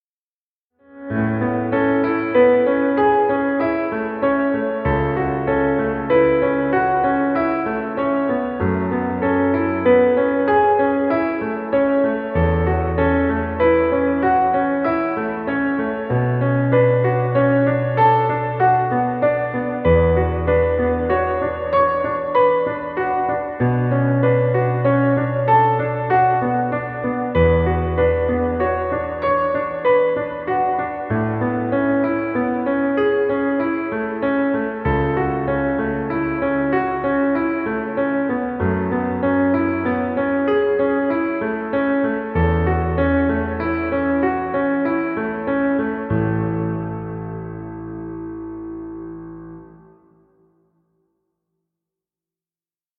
Piano romantic music.
Stock Music.